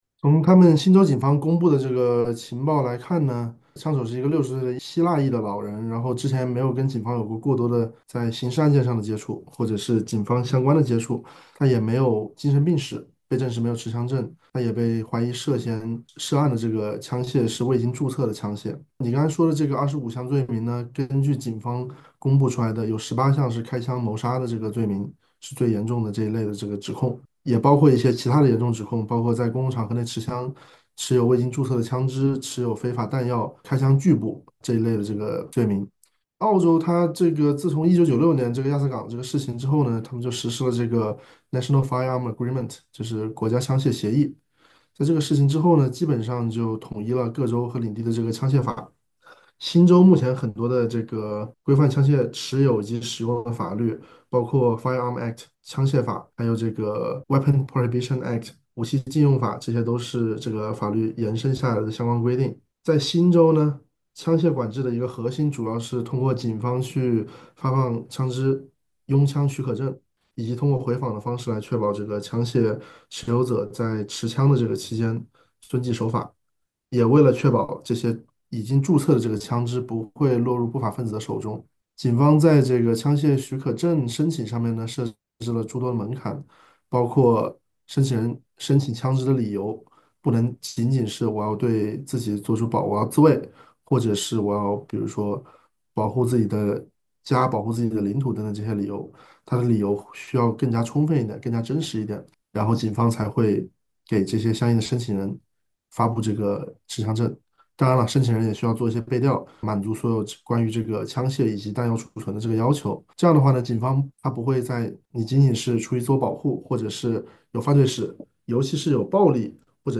SBS 普通话电台